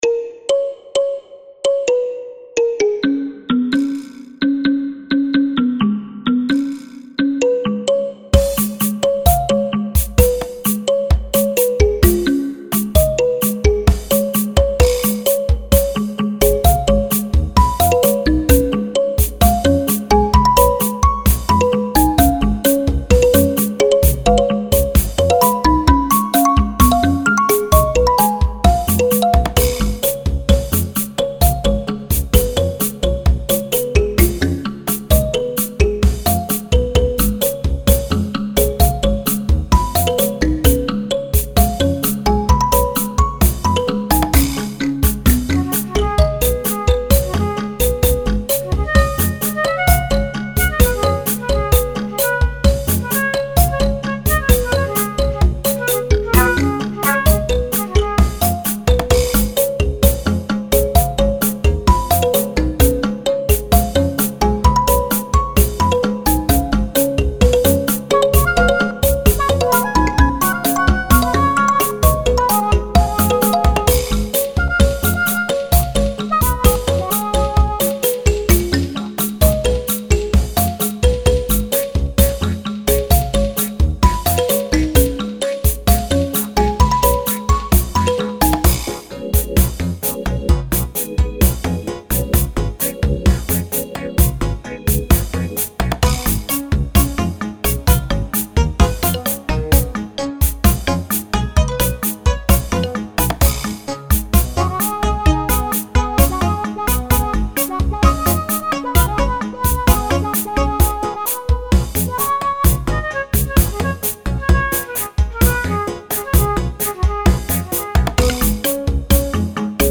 Worldbeat on Kalimba and Balafon
African Folk
Worldbeat (Kalimba; Marimba )